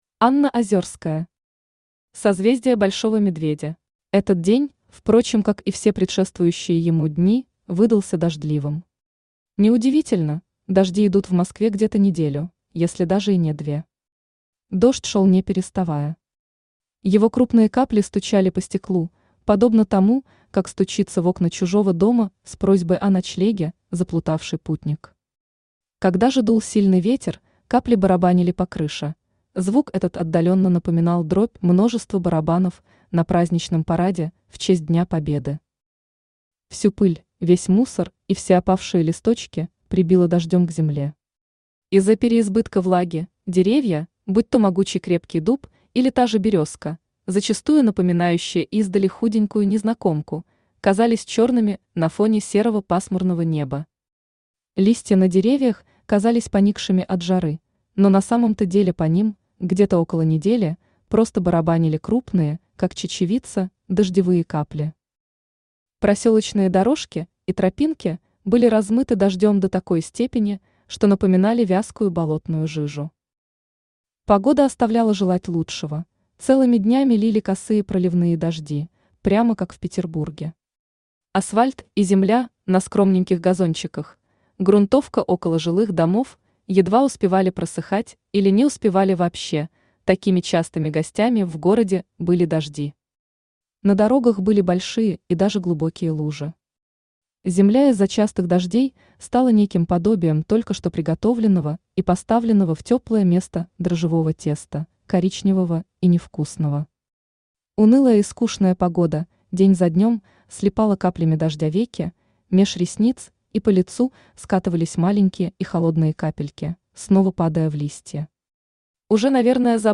Аудиокнига Созвездие Большого Медведя | Библиотека аудиокниг
Aудиокнига Созвездие Большого Медведя Автор Анна Озёрская Читает аудиокнигу Авточтец ЛитРес.